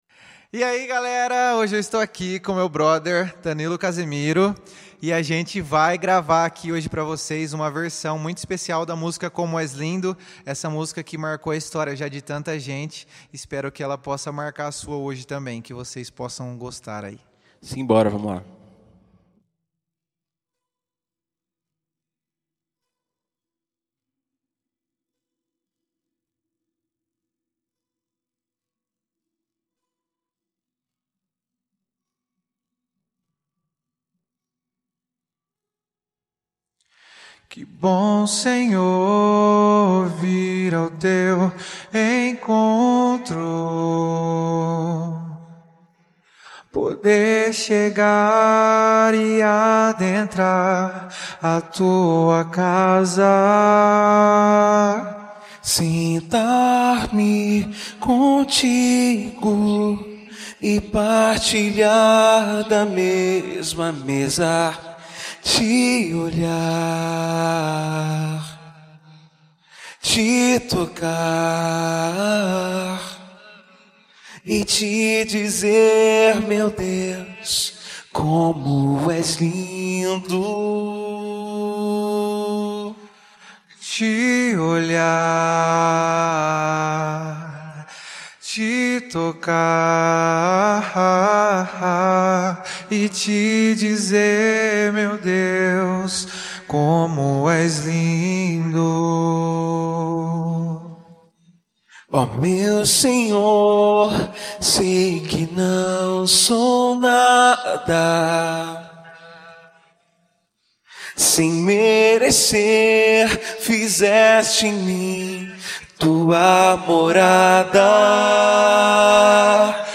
Stimme